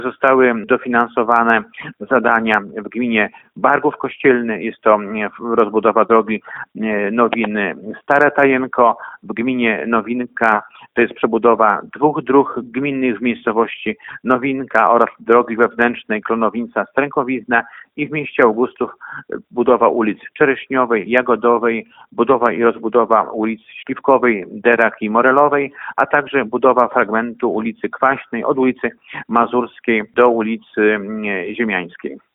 Powiat otrzymał 11,5 mln zł, które pokryją połowę kosztów zaplanowanych inwestycji. Jak mówi starosta Jarosław Szlaszyński, wykonane zostaną ważne drogowe remonty.